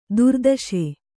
♪ durdaśe